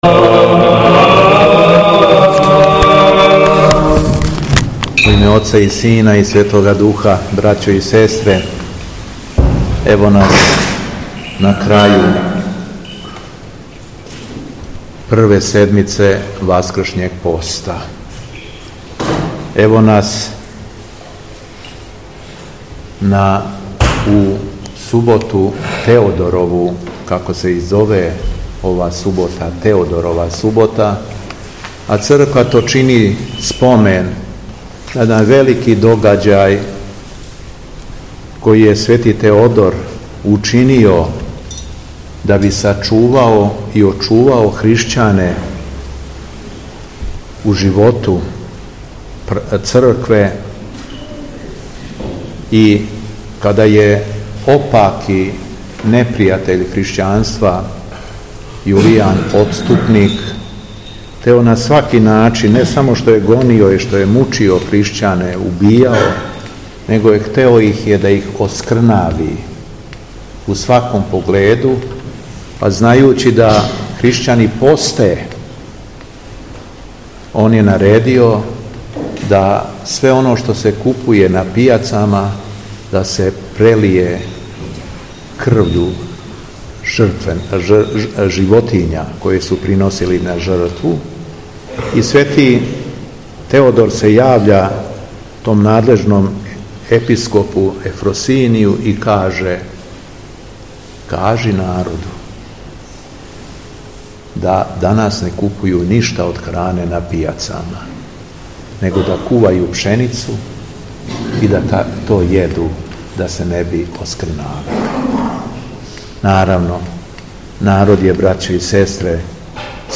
Беседа Његовог Високопреосвештенства Митрополита шумадијског г. Јована
Након прочитаног јеванђељског зачала, Митрополит се свештенству и верном народу обратио беседом: